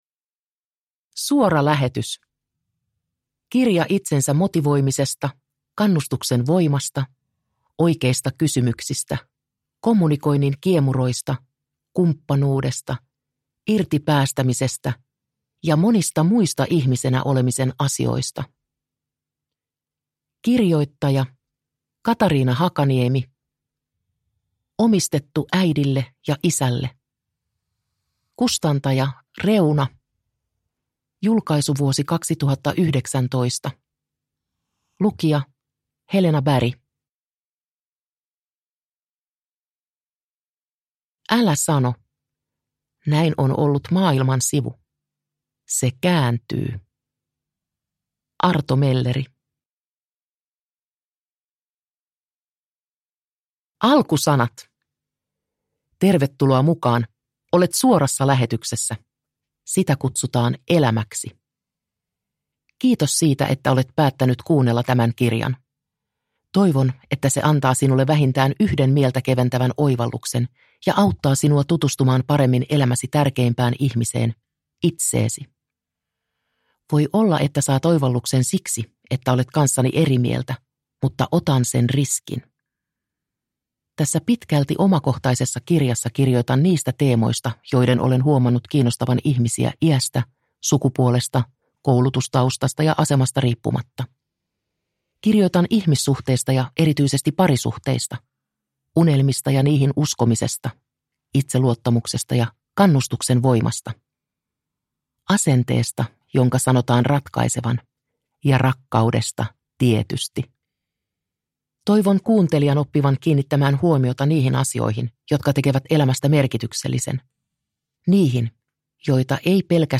Suora lähetys – Ljudbok – Laddas ner